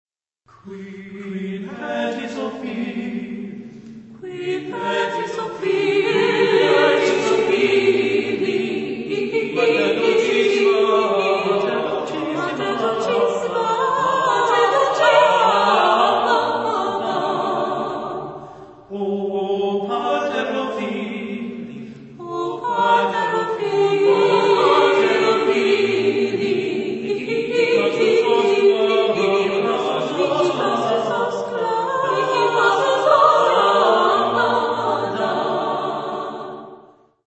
Epoque: 16th century  (1500-1549)
Genre-Style-Form: Imitation ; Renaissance ; Carol
Type of Choir: SATB  (4 mixed voices )